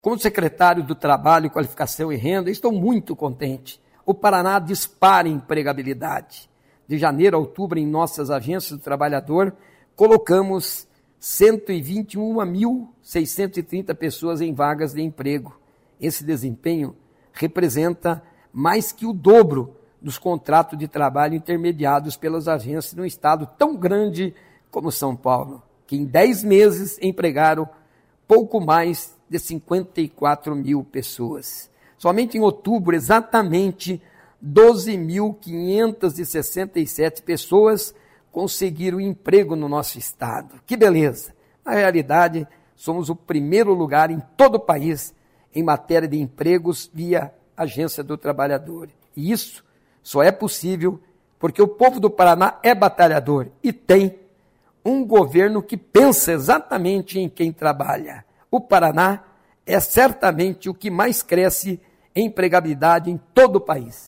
Sonora do secretário de Estado do Trabalho, Qualificação e Renda, Mauro Moraes, sobre o 10º mês de liderança do Paraná em empregabilidade via Agências do Trabalhador